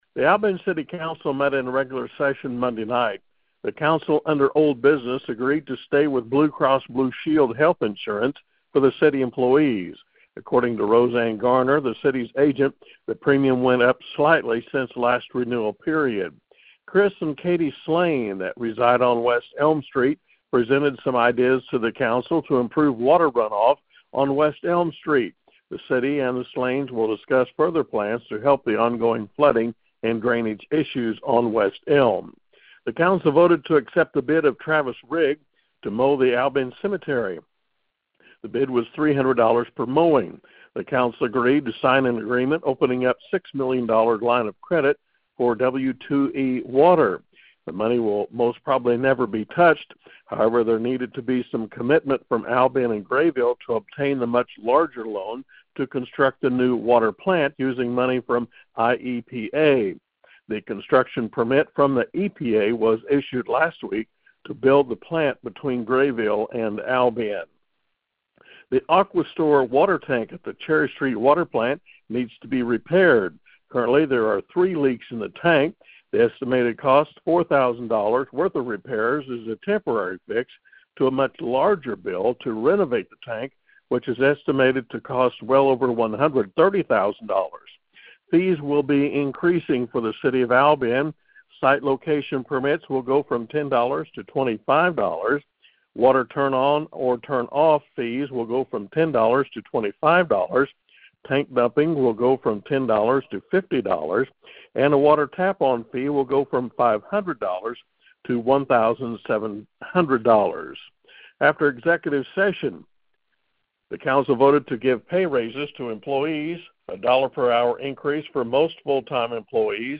ALBION CITY COUNCIL MEETING RECAP FROM MONDAY NIGHT